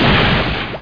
1 channel
00851_Sound_Bash.mp3